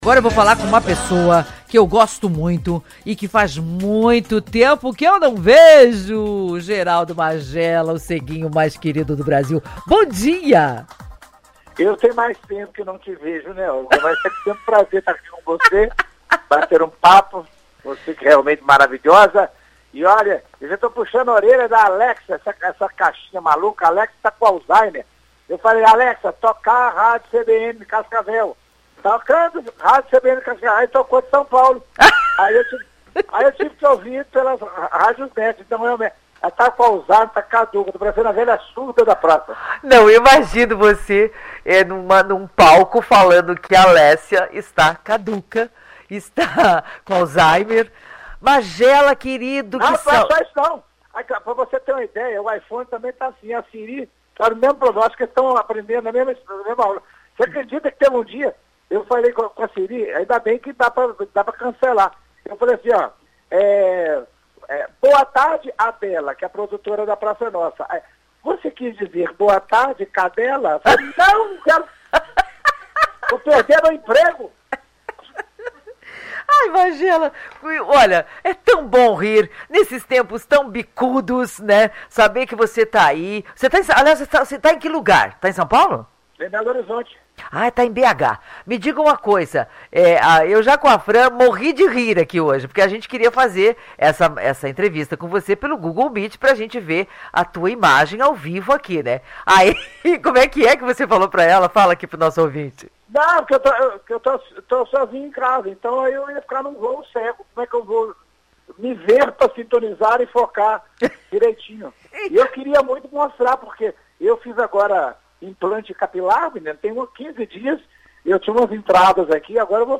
O humorista Geraldo Magela, o ceguinho, conta sobre a sua vida, os motivos que o fazem levar com bom humor a deficiência visual.